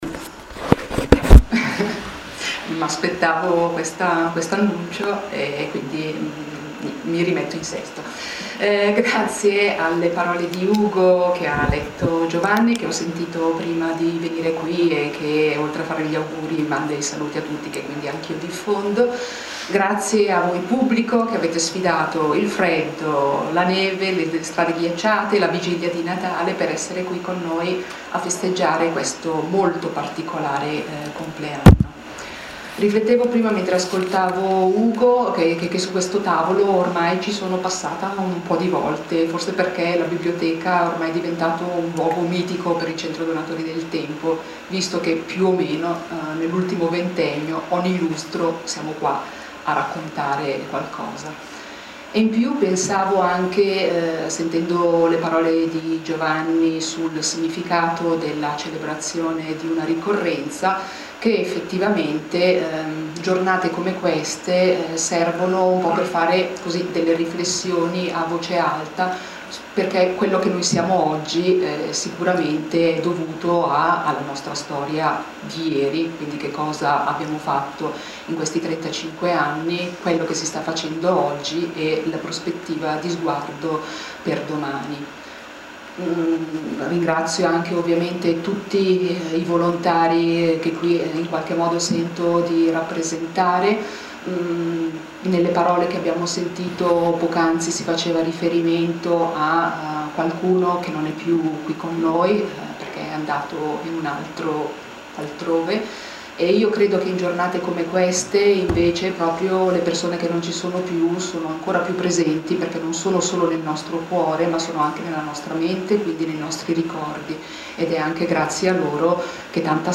CENTRO DONATORI DEL TEMPO, 1977 – 2012: 35° ANNIVERSARIO, Como 15 dicembre 2012.